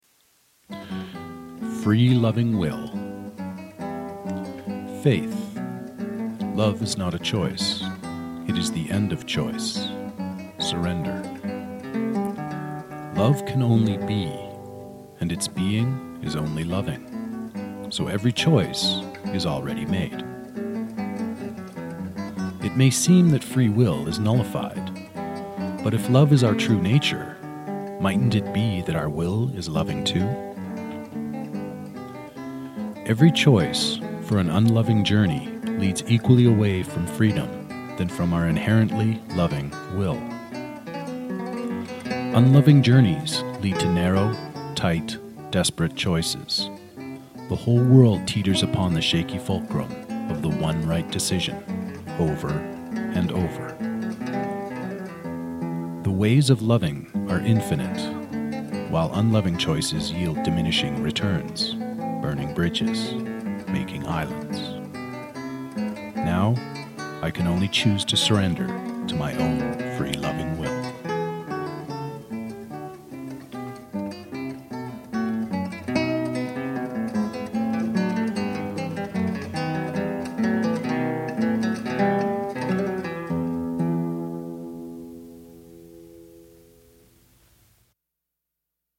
FreeLovingWill (Bach’s Bouree played by Andres Segovia)